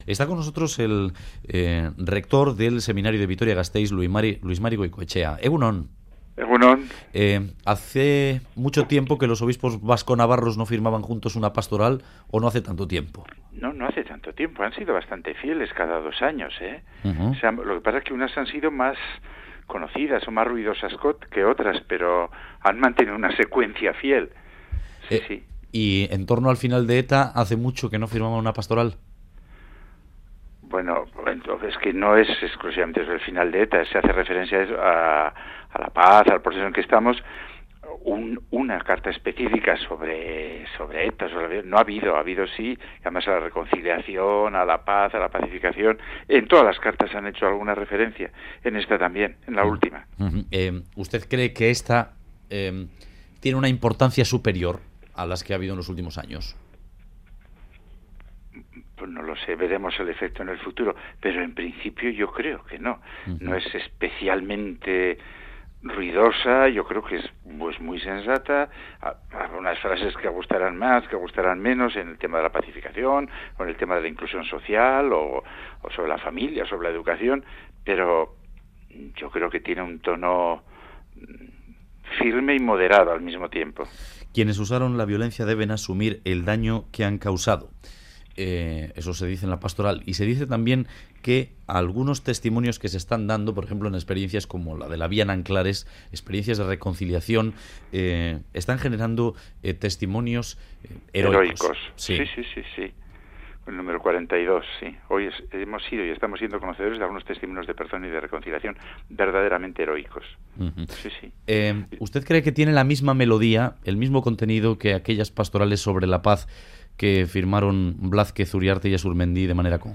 En entrevista al Boulevard de Radio Euskadi